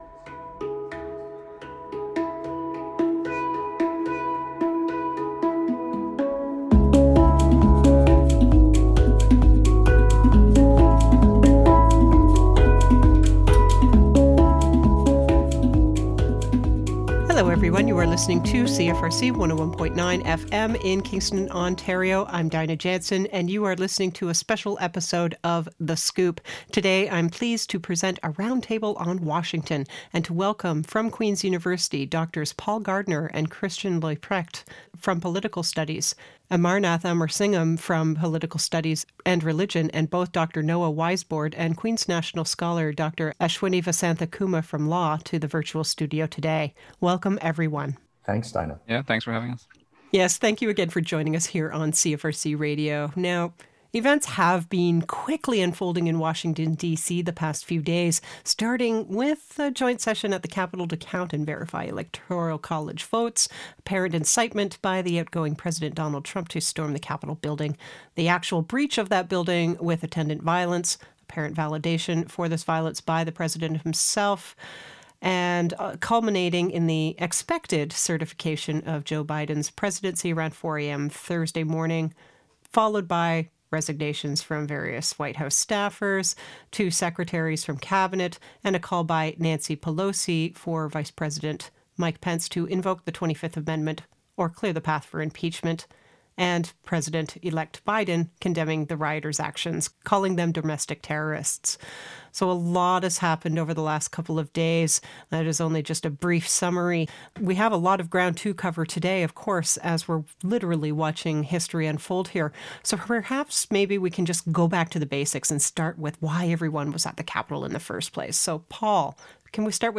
Roundtable on the Siege on the Capitol